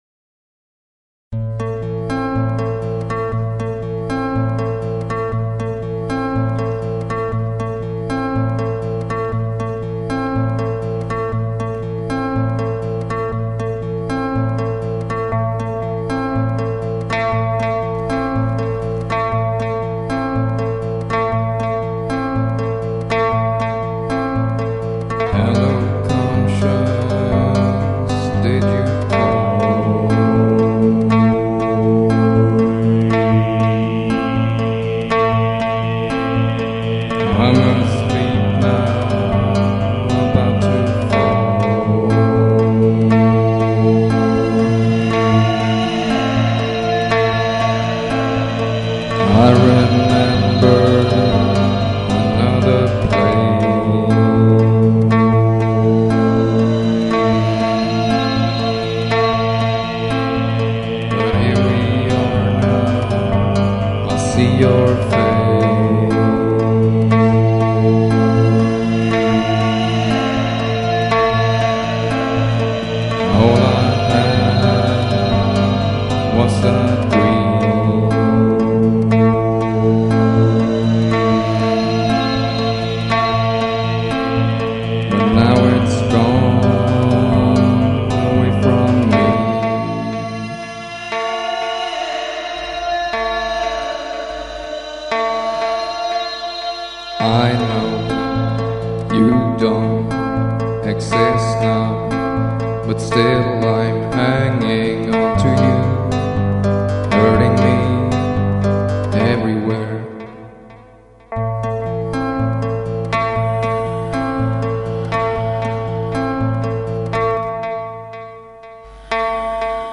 En önskvärd liten hemmastudio...
kör och inspiration